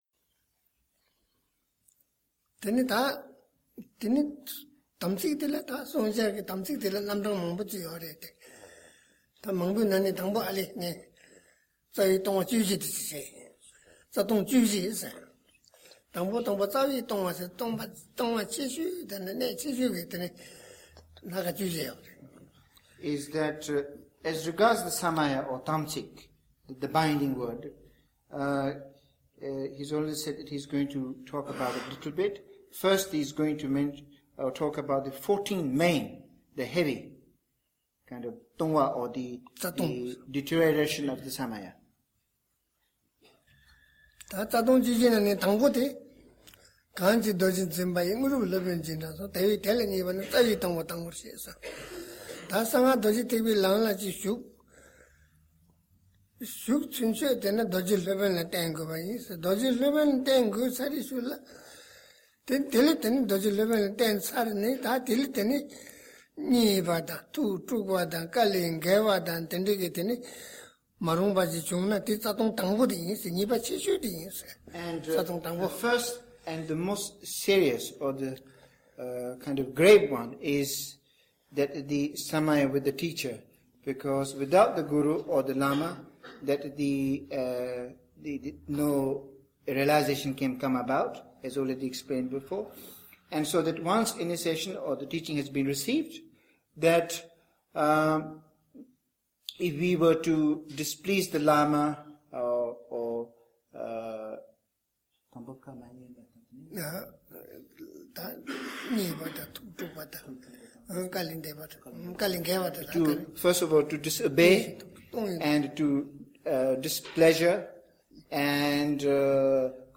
Teaching on the 14 Root Downfalls